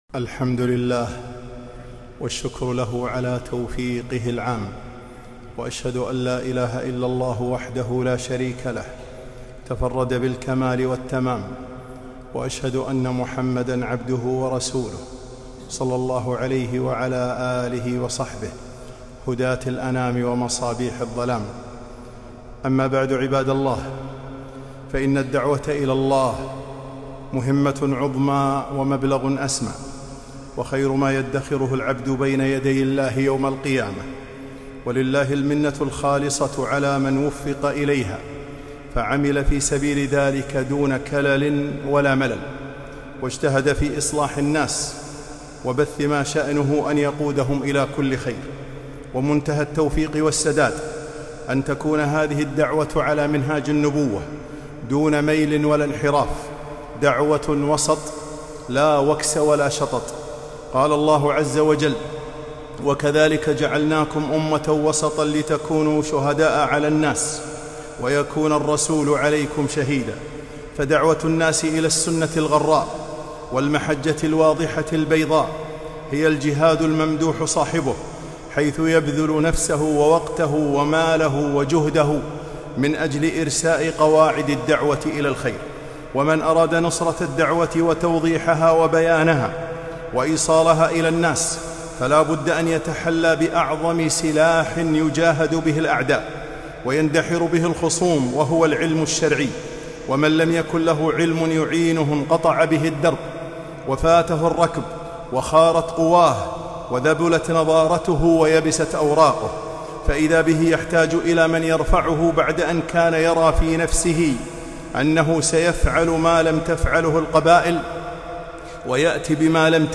خطبة - الطريق إلى دعوة مثمرة